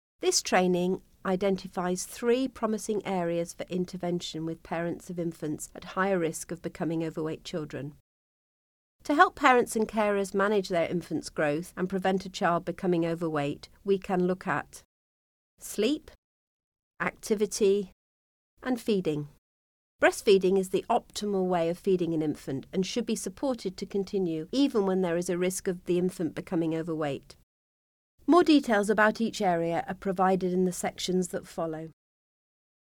Sleep management Responsive feeding Healthy nutrition Increase physical activity Reduce sedentary activity Previous Next Narration audio (MP3) Narration audio (OGG)